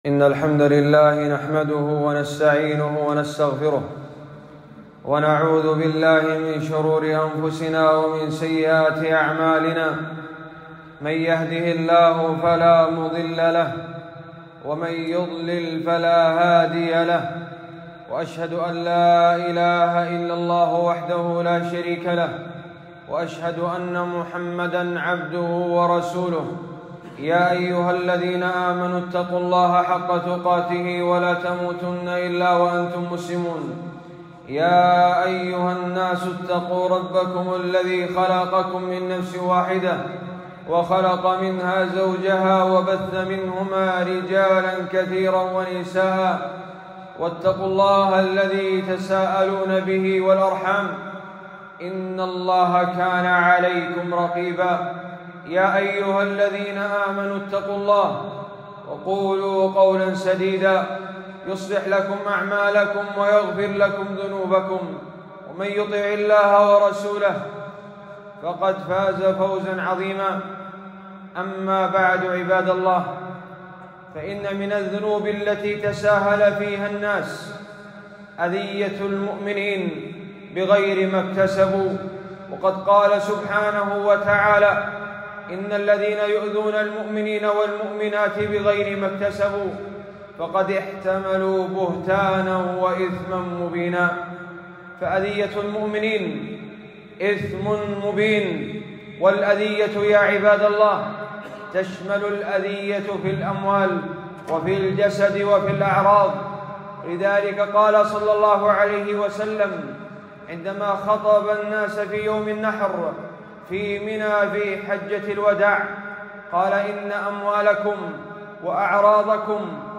خطبة - الإثم المبين في أذية المؤمنين